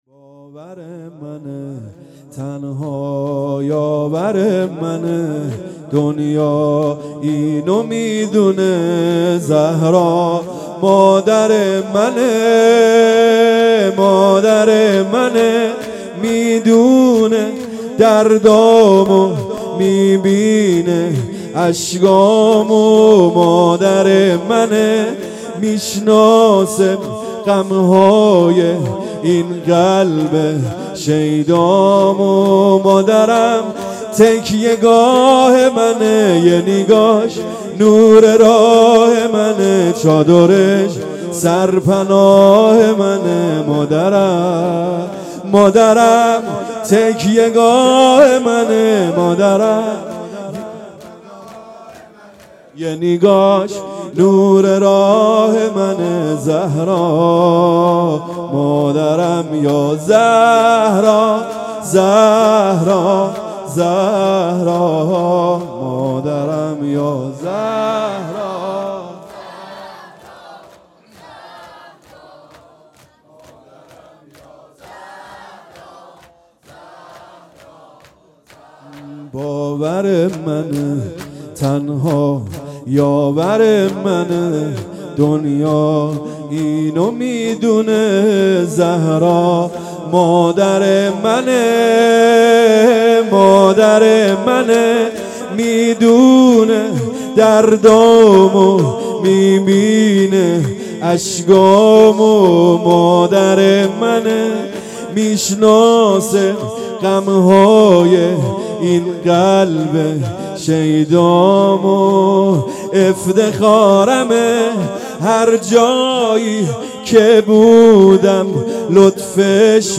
هيأت یاس علقمه سلام الله علیها
شهادت حضرت فاطمه سلام الله علیها